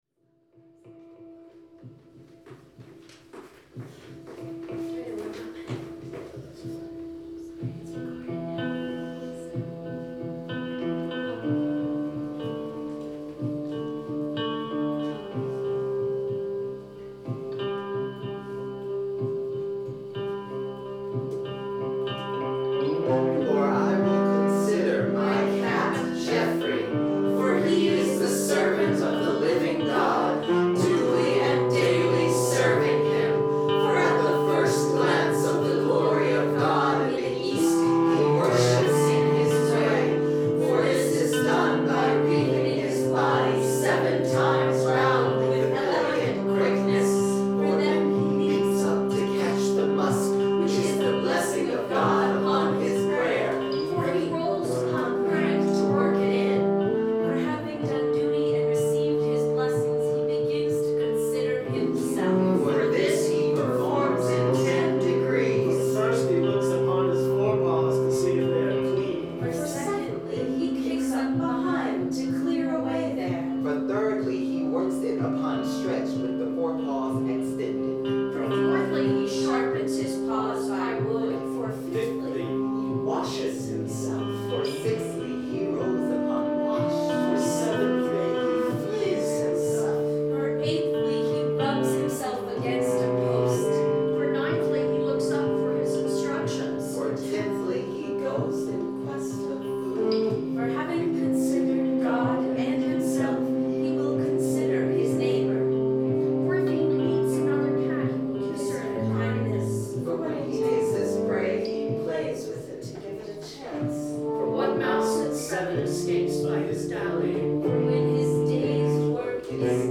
(A joyous, riotous celebration of the cat, lapsing into occasional moments of meditative contemplation of the cat. Structured around unison choruses.)